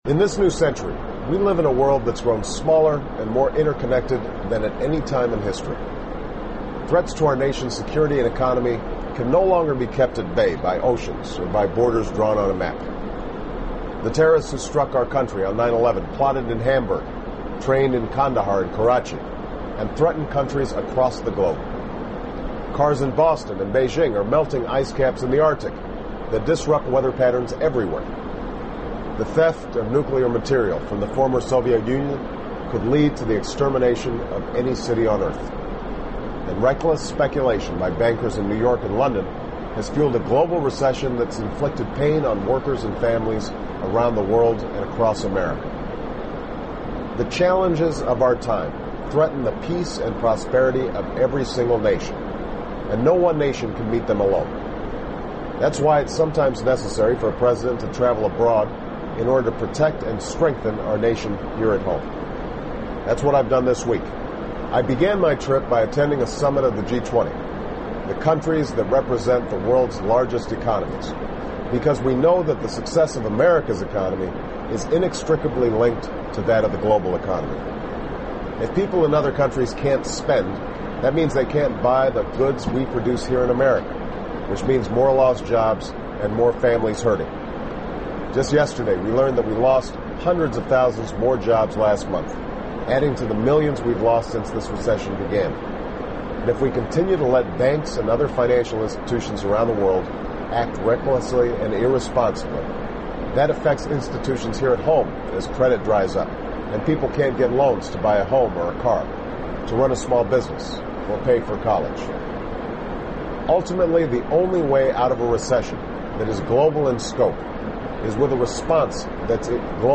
【美国总统电台演说】2009-04-04 听力文件下载—在线英语听力室